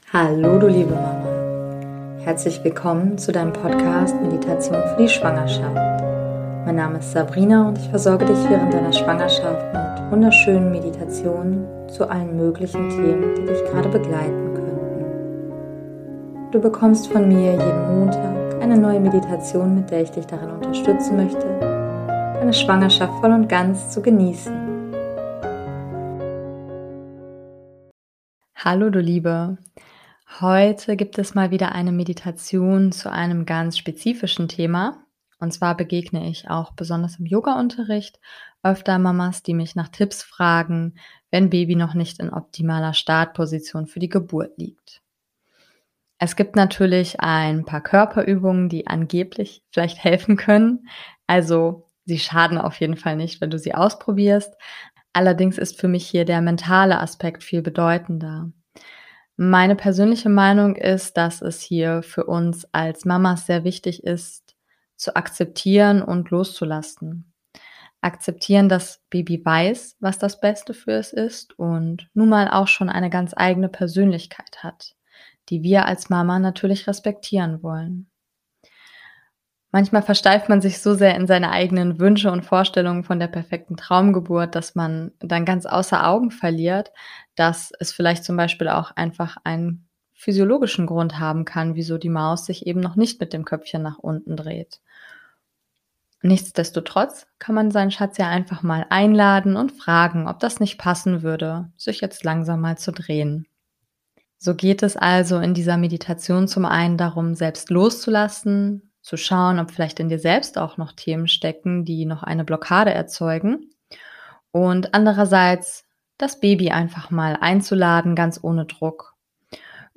#041 - Beckenendlage Meditation - Lade dein Baby ein, sich zu drehen ~ Meditationen für die Schwangerschaft und Geburt - mama.namaste Podcast